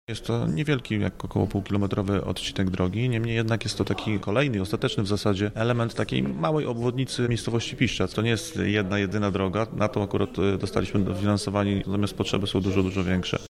Więcej o inwestycji mówi Kamil Kożuchowski, wójt gminy Piszczac.